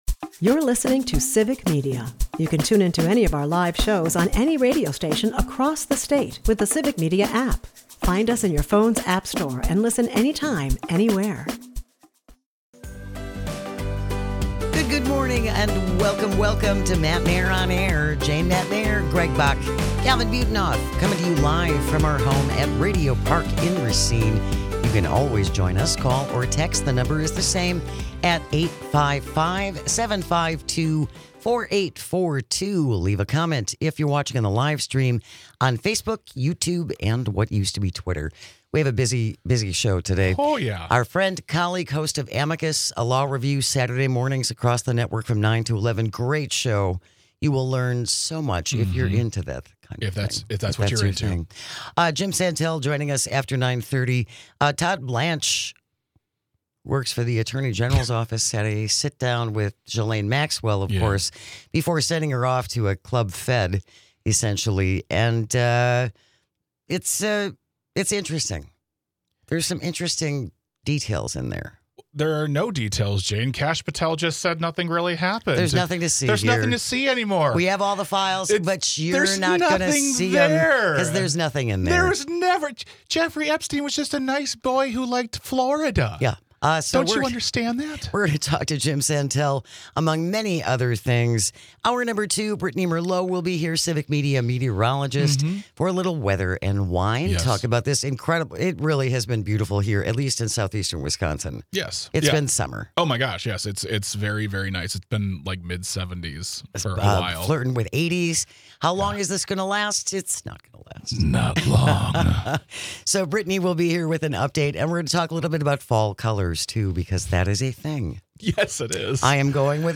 Then, our very own Jim Santelle joins the show to talk about all the news coming out of the world of law including an interview with Todd Blanche and the new narrative of Ghislaine Maxwell .
Matenaer On Air is a part of the Civic Media radio network and airs weekday mornings from 9-11 across the state.